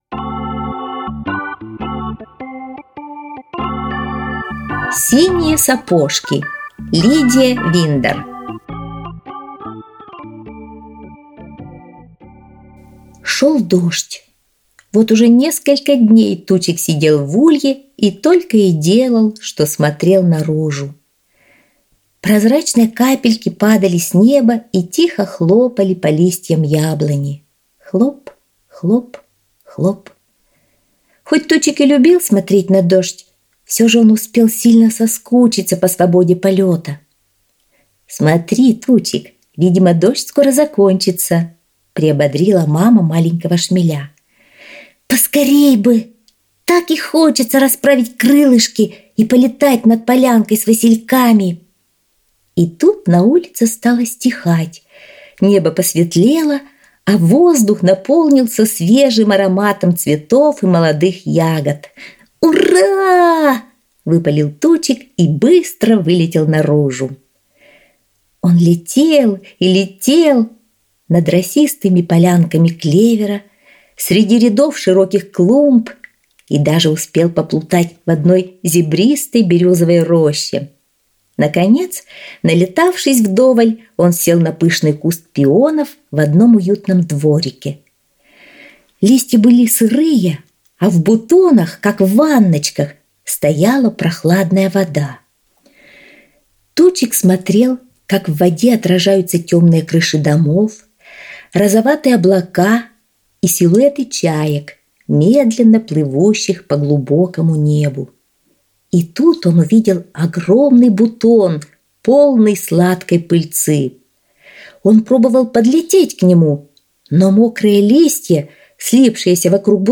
Аудиосказка «Синие сапожки»